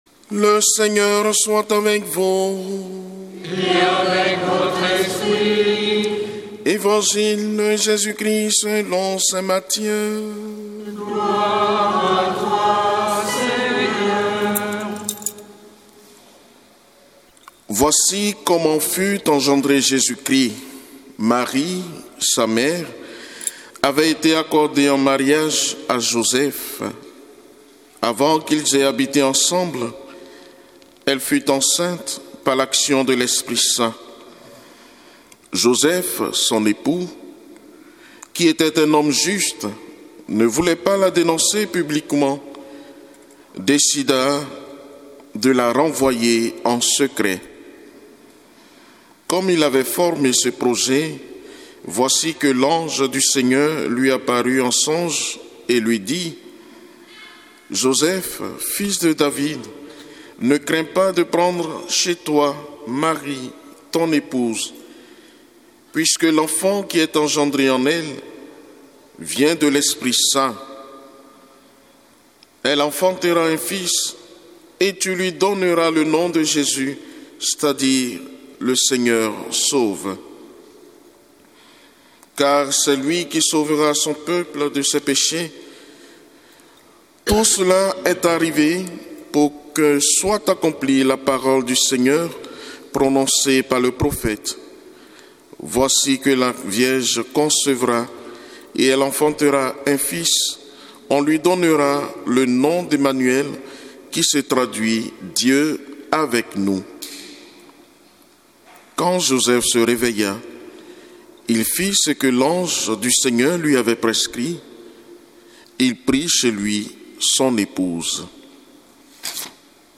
Évangile de Jésus Christ selon saint Matthieu avec l'homélie